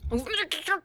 speech.wav